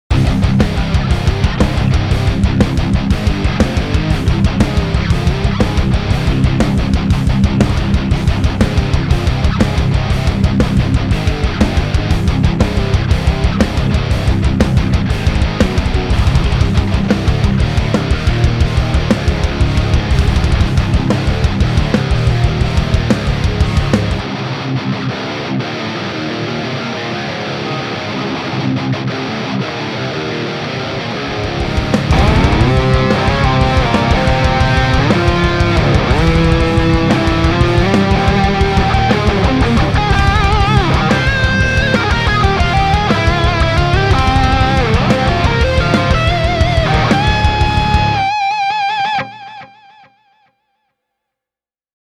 Moderni metalli ei ole oma leipälajini, joten käännyin genreen enemmän perehtyneen puoleen.
Kitararaidat on äänitetty Atomic Amps AmpliFire -mallintajan kautta, käyttäen Tube Screamerilla boostattua Friedman BE-mallinnusta, Zilla Fatboy 2×12 -impulssivasteen läpi ajettuna: